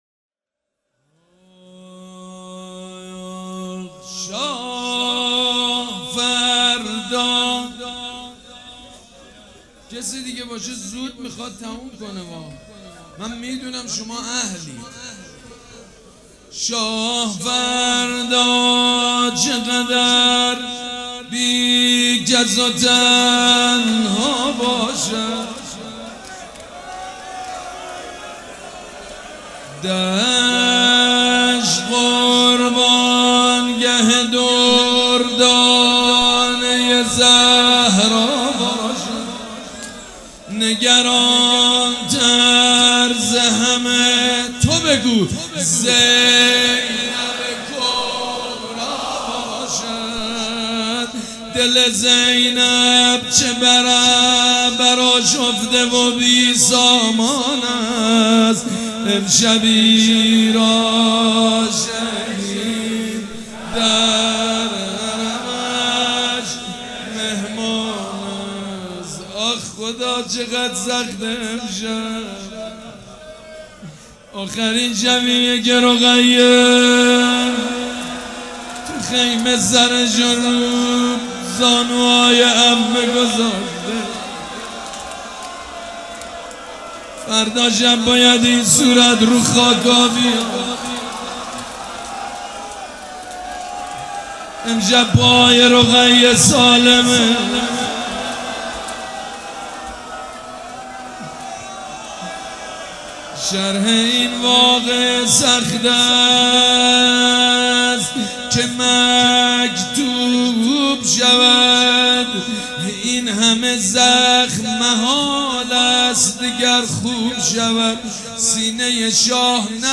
روضه
مداح
مراسم عزاداری شب عاشورا